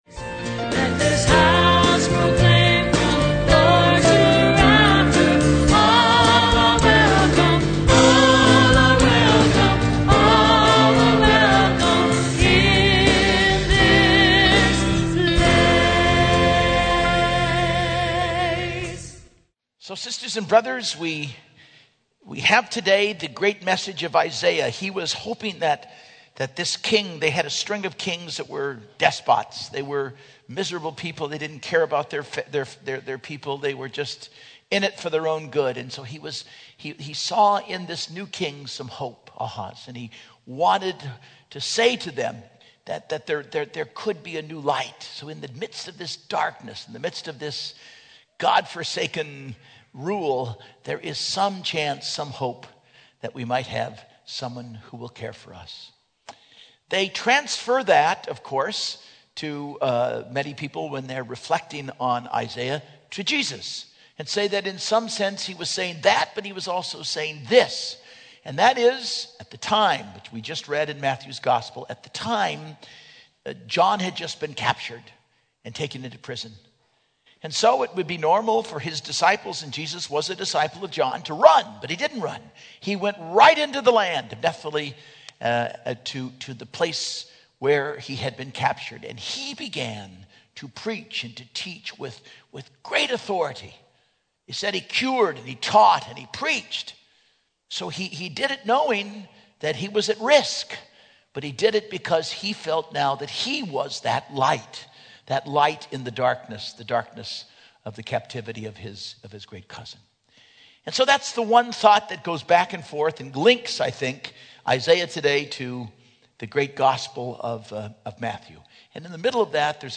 Homily - 1/23/11 - 3rd Sunday Ordinary Time